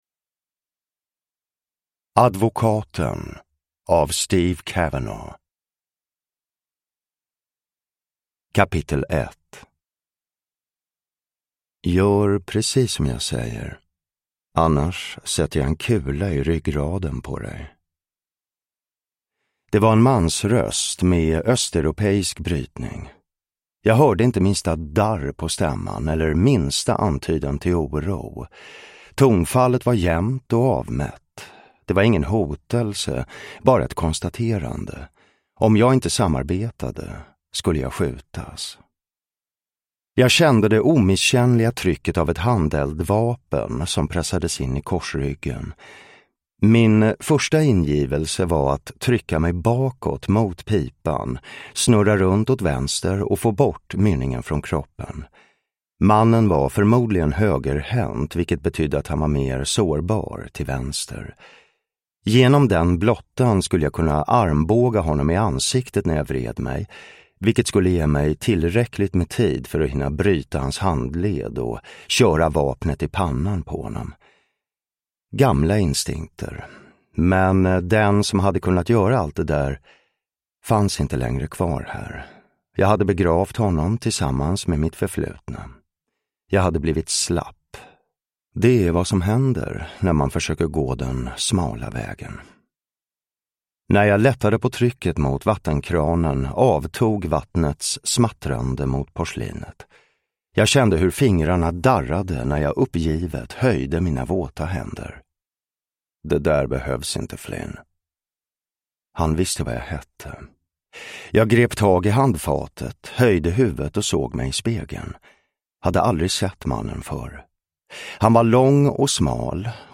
Advokaten – Ljudbok
Uppläsare: Jonas Malmsjö